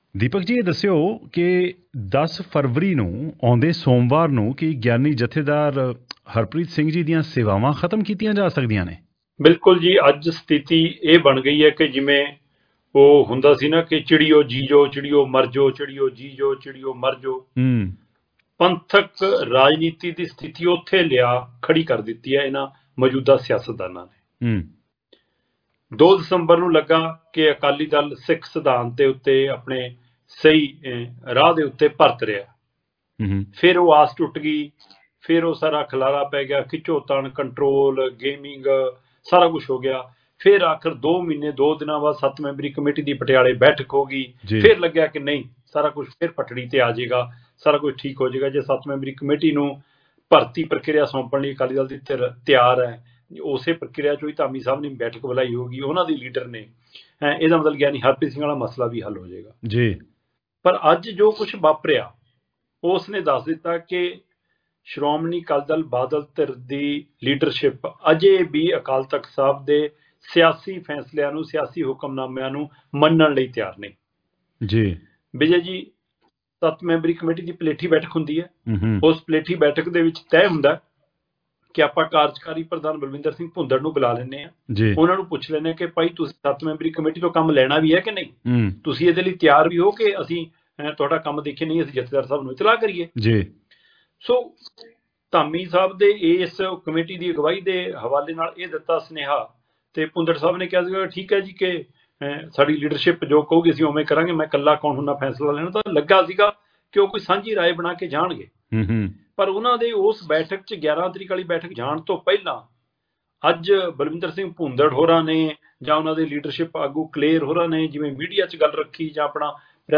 lead engaging discussions and provide in-depth analysis of the latest political developments.